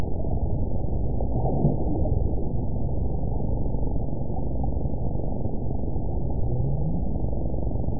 event 918208 date 08/14/23 time 16:02:07 GMT (2 years, 4 months ago) score 8.81 location TSS-AB04 detected by nrw target species NRW annotations +NRW Spectrogram: Frequency (kHz) vs. Time (s) audio not available .wav